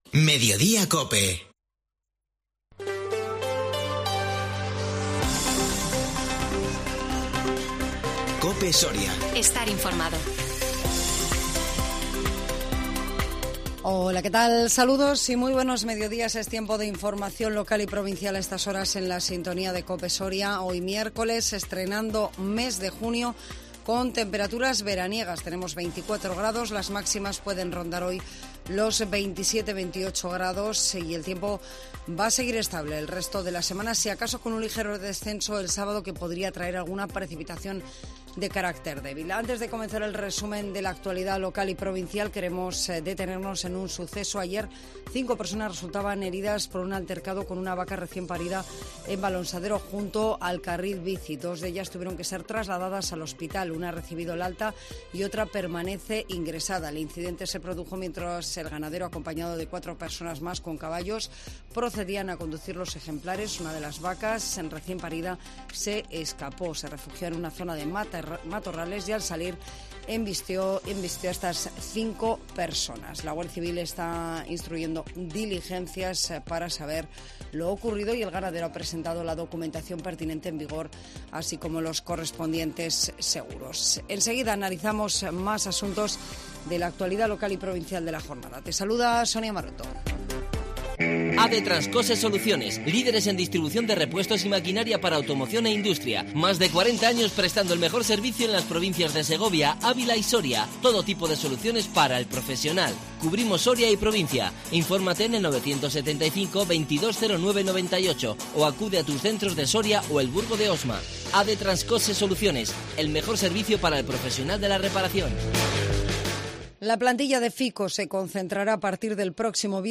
INFORMATIVO MEDIODÍA COPE SORIA 1 JUNIO 2022